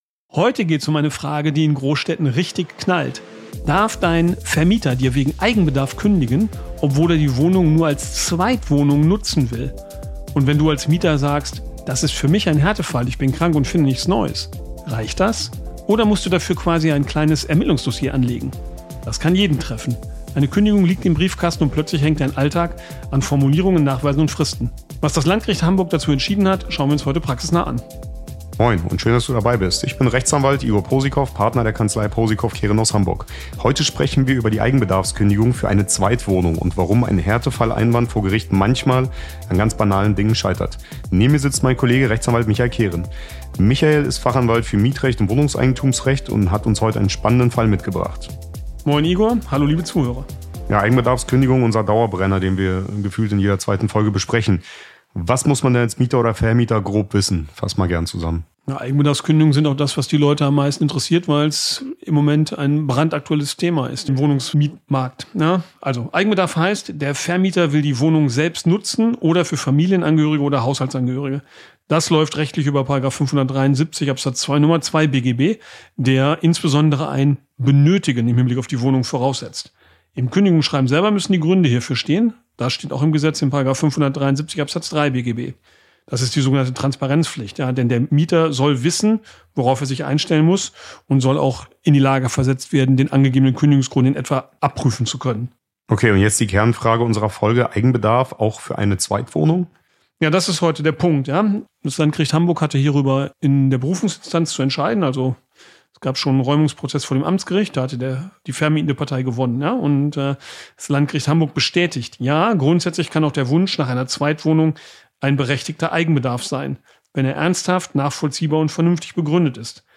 Law&Talk wird von den spezialisierten Anwälten der Posikow Kehren Partnerschaft mbB moderiert und bietet unkompliziert, praxisnahe Rechtstipps und die Besprechung aktueller Gerichtsurteile, die Ihnen bei der Bewältigung alltäglicher rechtlicher Herausforderungen helfen.